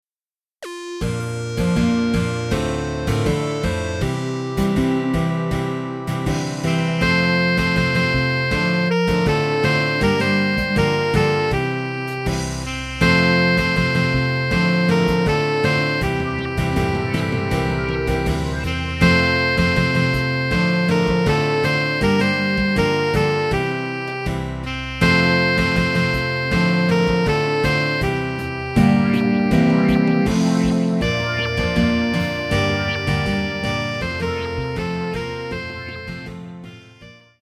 Sample from the Backing MP3